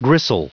Prononciation du mot gristle en anglais (fichier audio)
Prononciation du mot : gristle